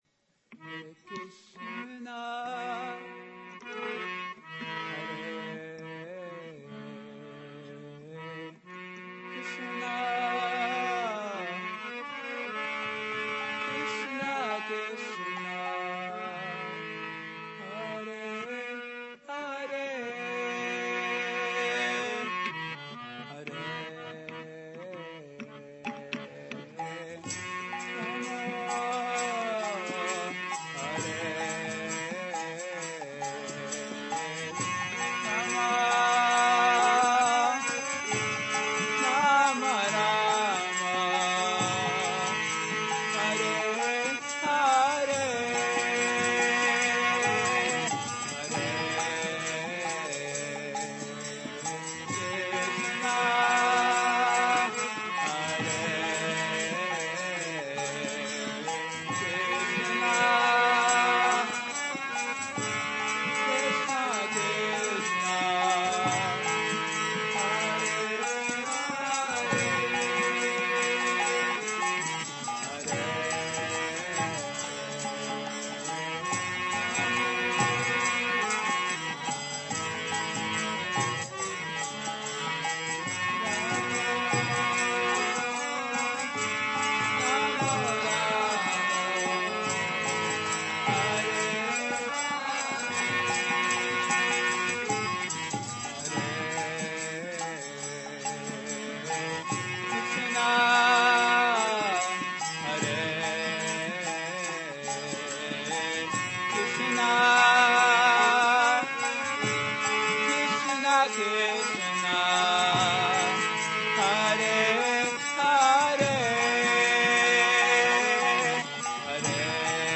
Hare Krsna Kirtana